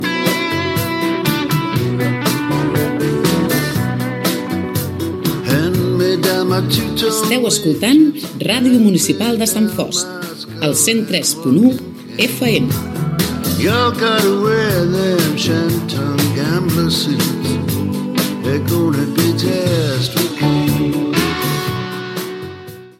Tema musical i indicatiu de l'emissora